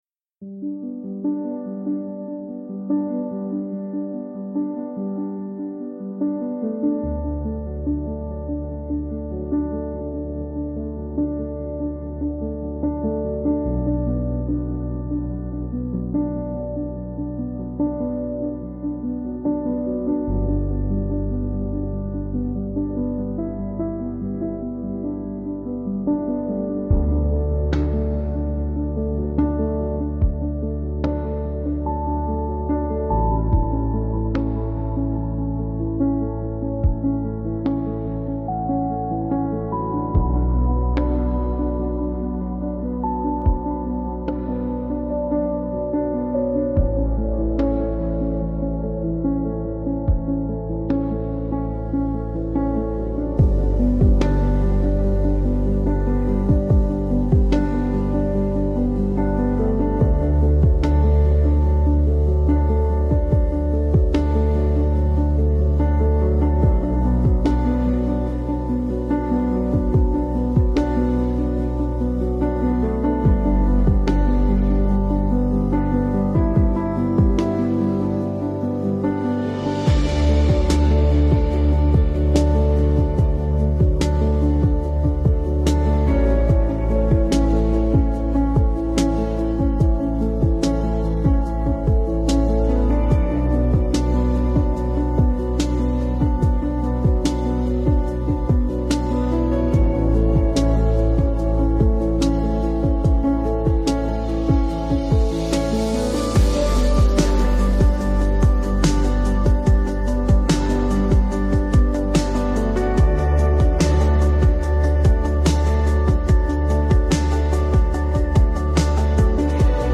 528 Hz für innere Ausrichtung
Beschreibung vor 2 Wochen Dieses Klangfeld ist für dich, wenn du spürst, dass sich etwas in dir neu ausrichten möchte. 528 Hz wird oft mit Harmonie, innerer Ordnung und Herzöffnung verbunden. Dieses Stück begleitet dich dabei, wieder bei dir anzukommen – ruhig, klar und ohne Druck.